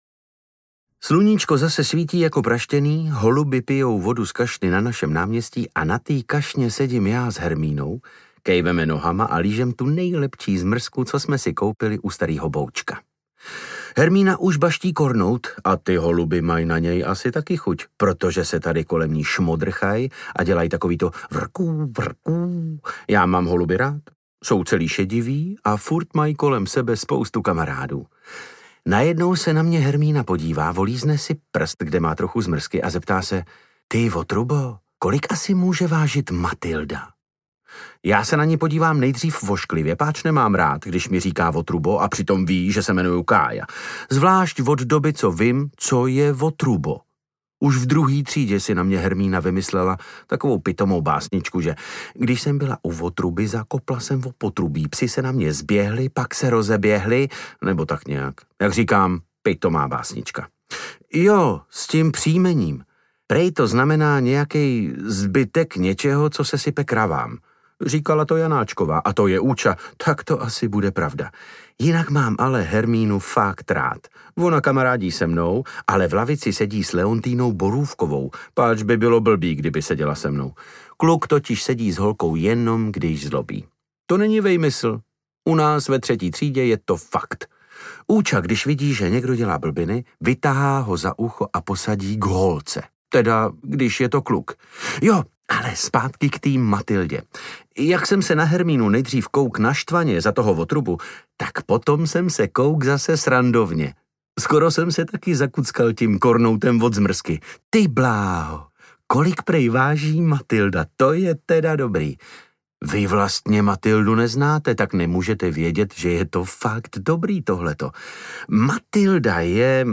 Kolik váží Matylda? audiokniha
Ukázka z knihy
• InterpretDavid Novotný